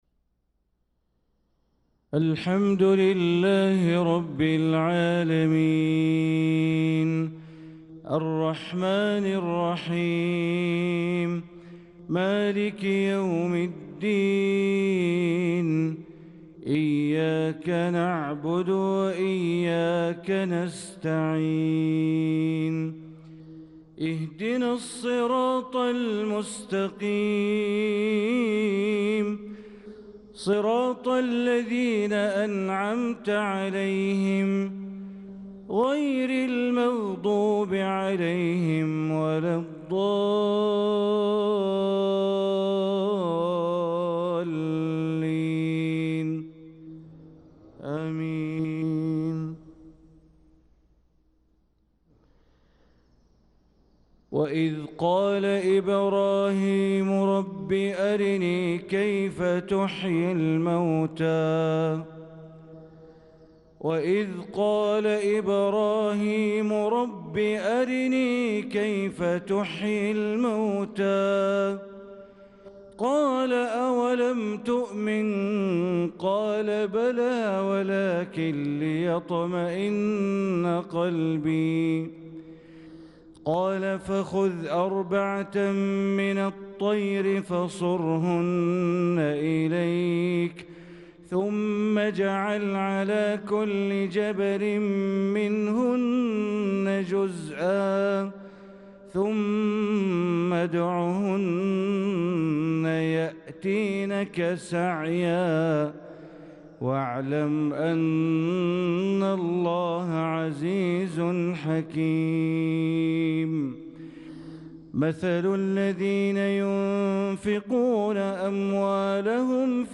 صلاة الفجر للقارئ بندر بليلة 20 ذو القعدة 1445 هـ
تِلَاوَات الْحَرَمَيْن .